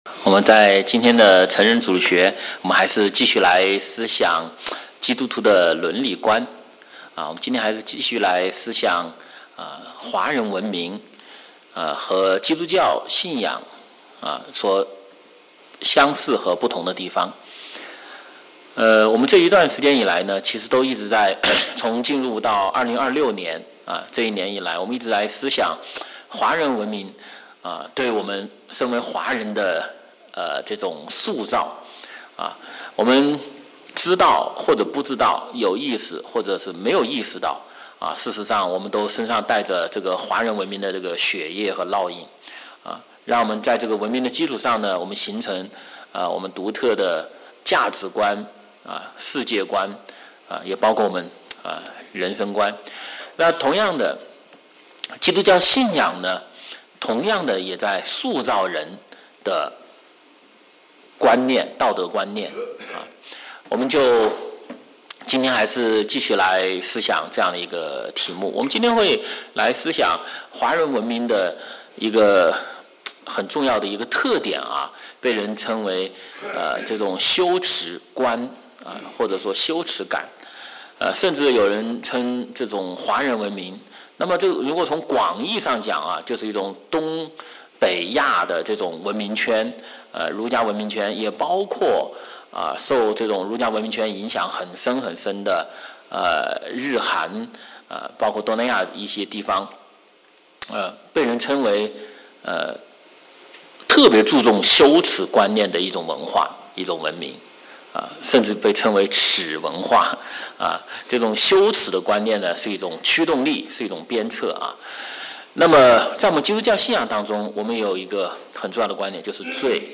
Series: Sunday School